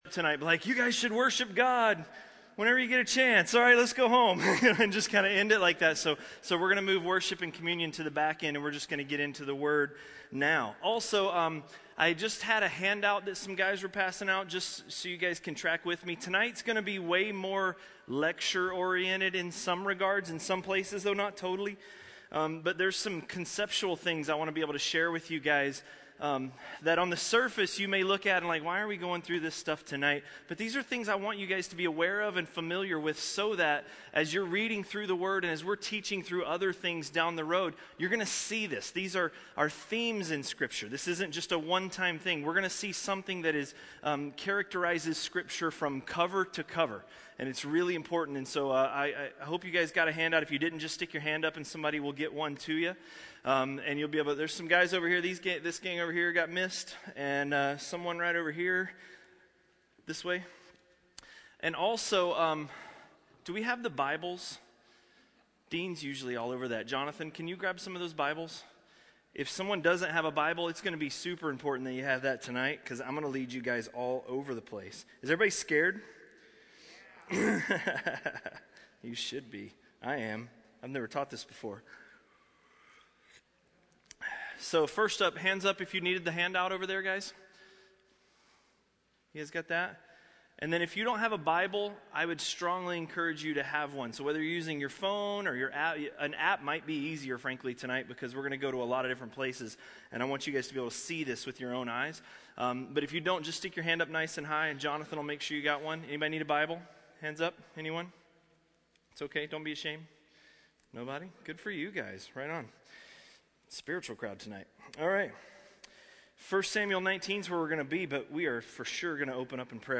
A message from the series "1 Samuel." 1 Samuel 19